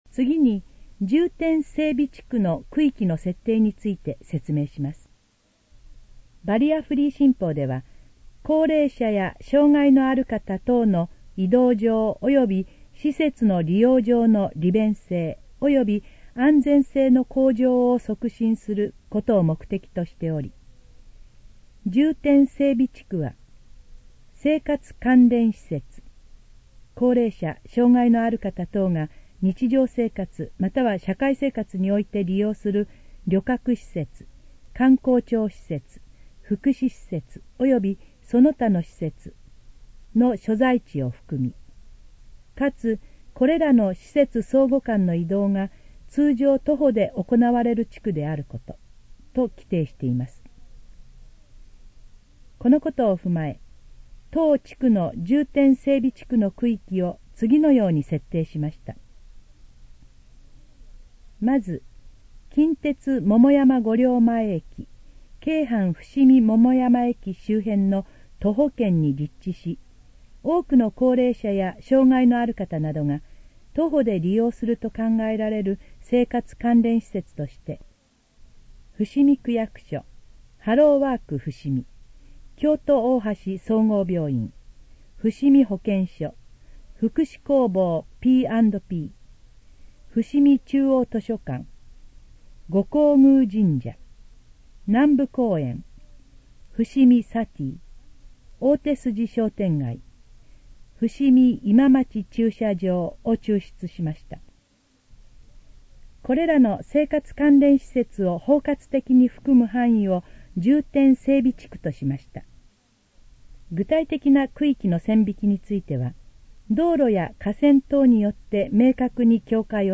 このページの要約を音声で読み上げます。
ナレーション再生 約534KB